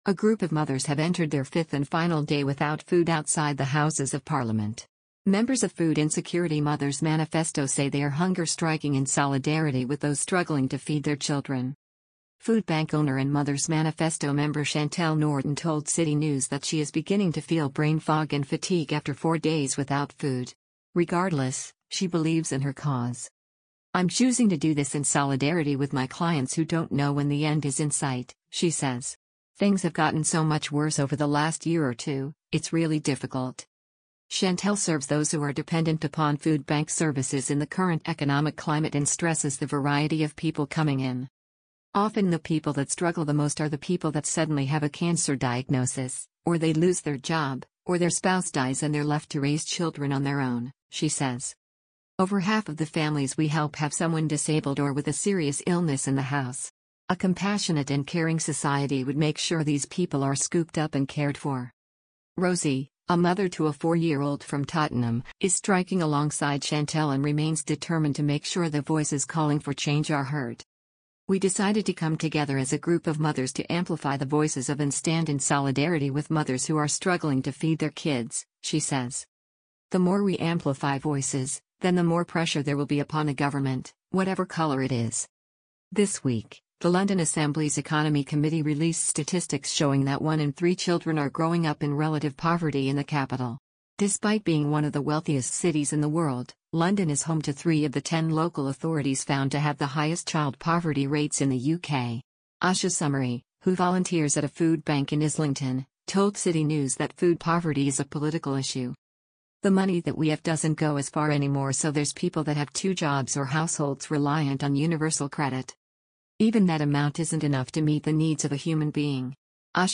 Listen to this article powered by AI